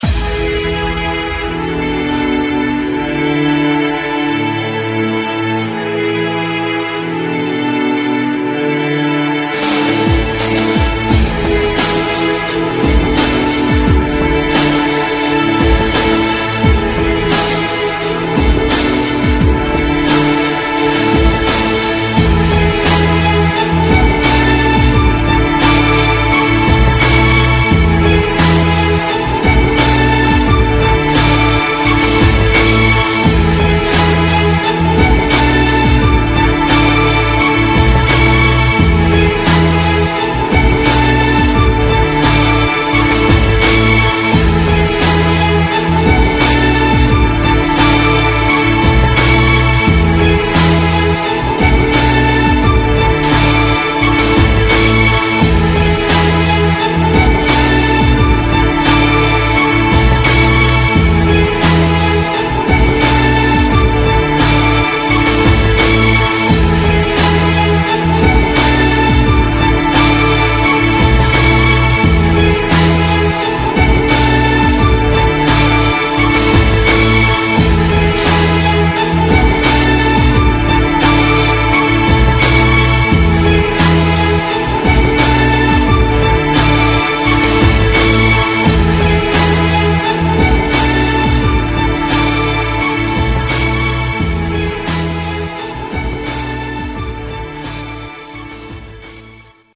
nice background music.